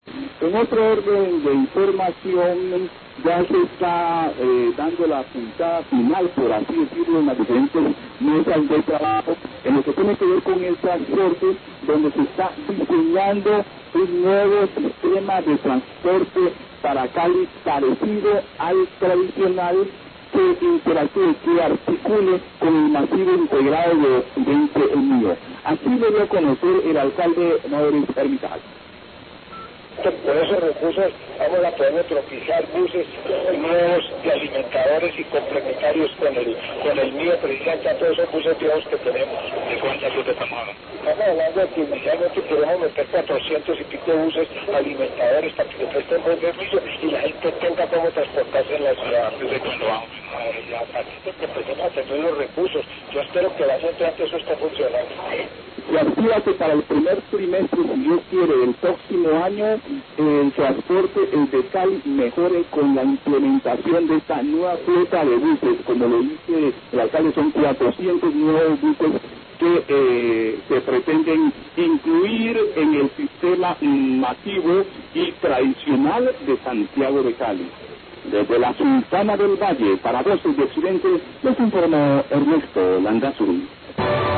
Radio
El alcalde de Cali, Maurice Armitage, expone que se está diseñando un nuevo sistema de transporte para Cali con el fin de que se articule con el Masivo Integrado de Occidente (MIO), el cual estaría implementándose para el primer trimestre del próximo año.